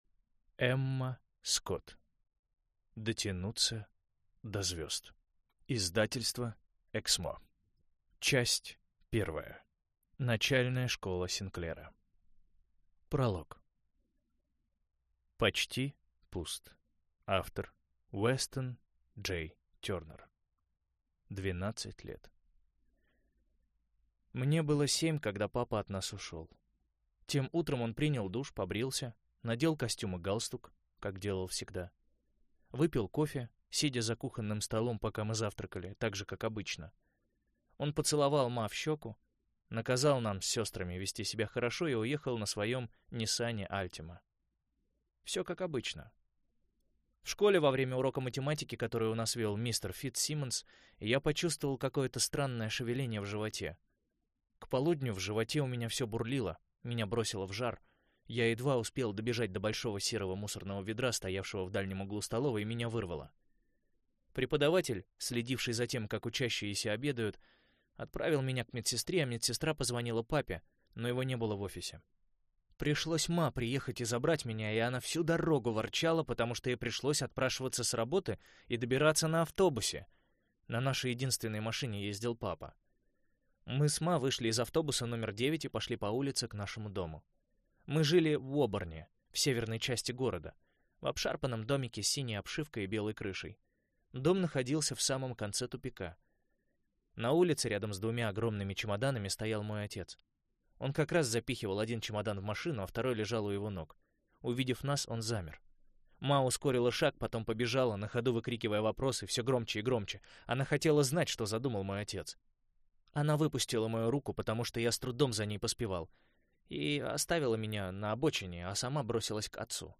Аудиокнига Дотянуться до звёзд | Библиотека аудиокниг